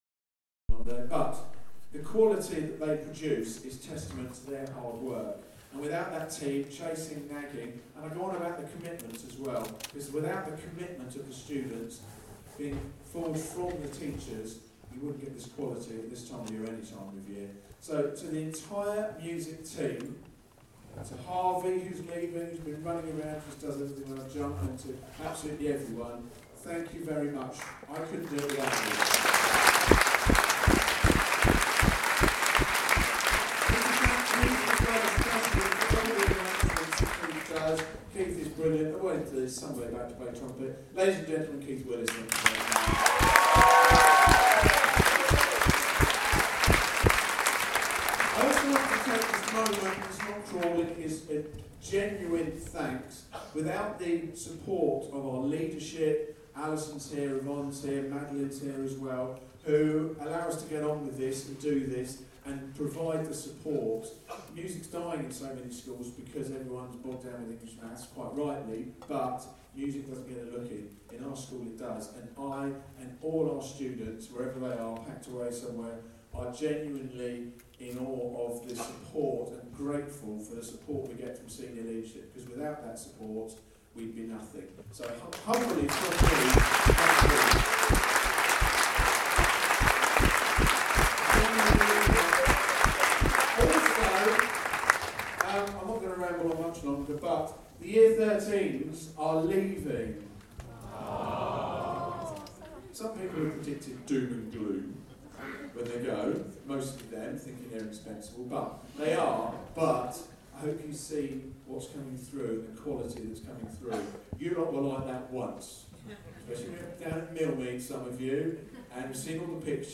big band